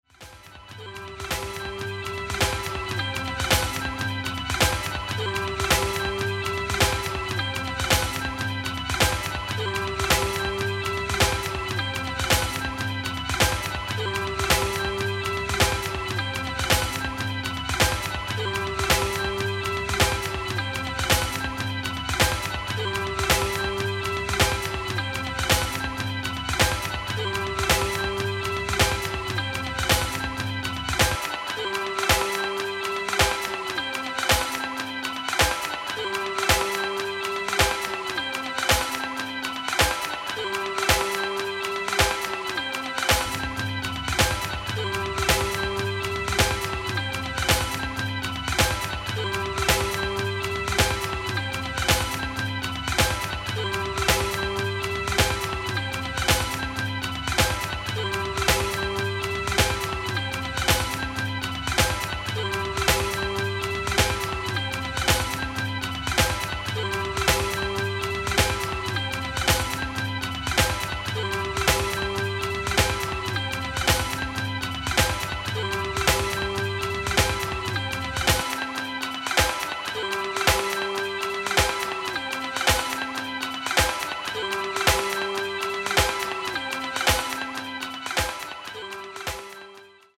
An extended re-edit